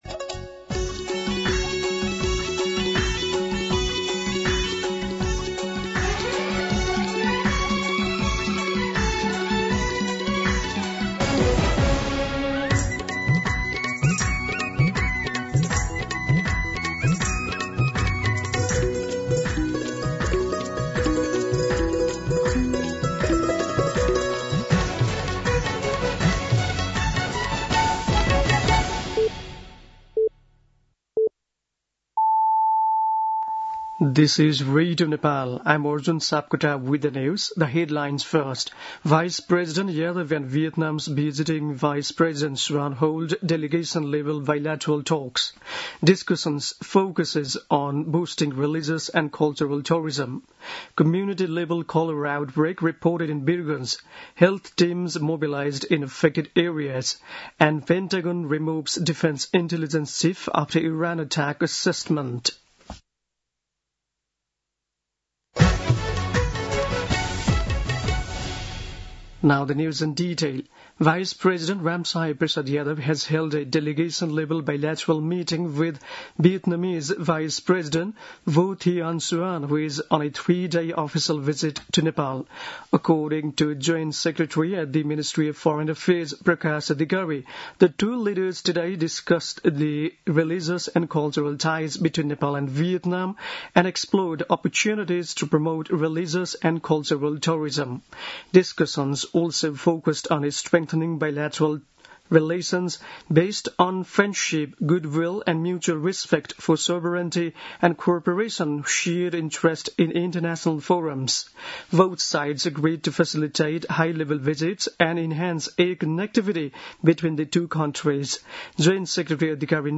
दिउँसो २ बजेको अङ्ग्रेजी समाचार : ८ भदौ , २०८२
2pm-English-News-08.mp3